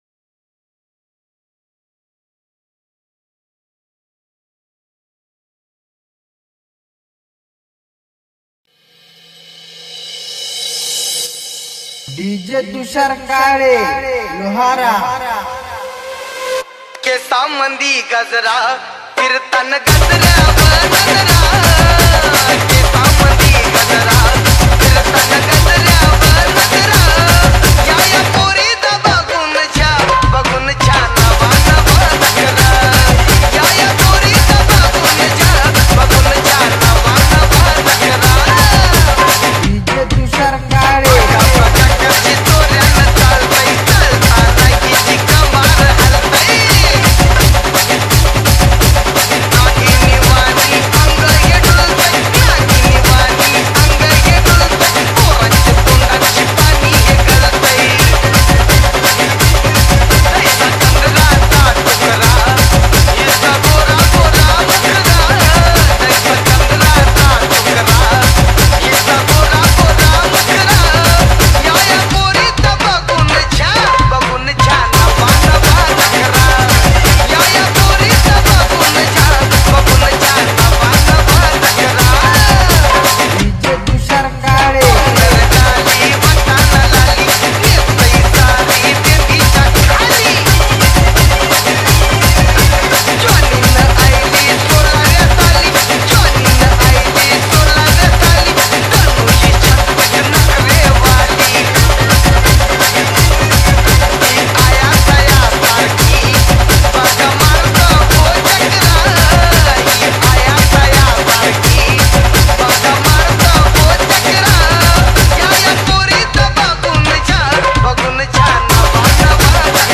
Full Tapori Mix